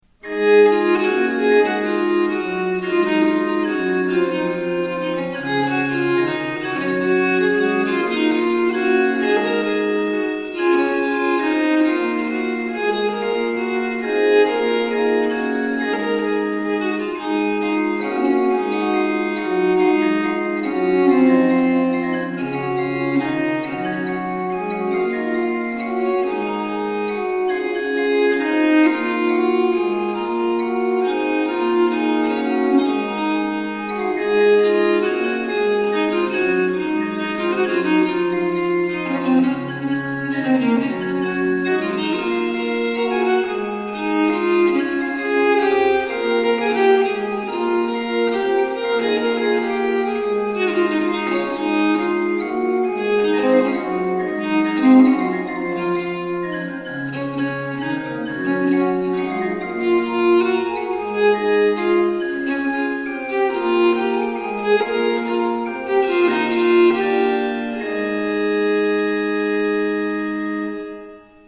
Huic ut placuit — (strumentale)
viola da gamba e organo